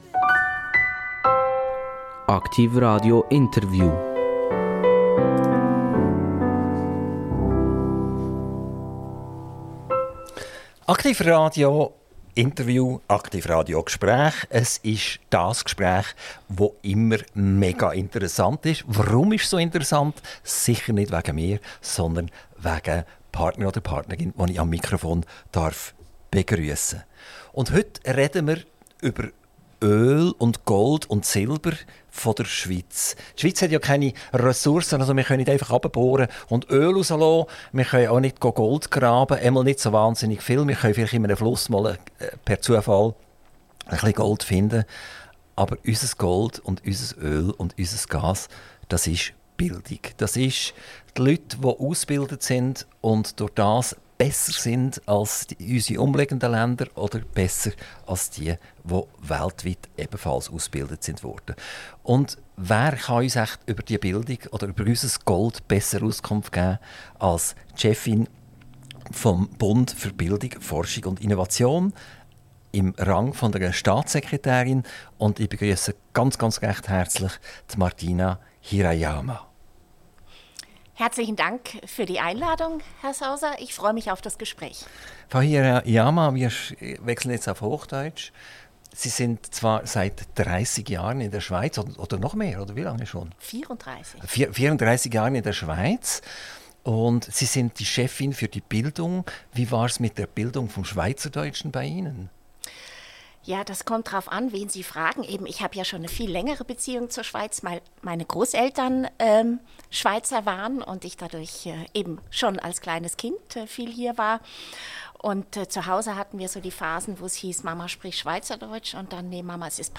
INTERVIEW - Martina Hirayama - 24.06.2024 ~ AKTIV RADIO Podcast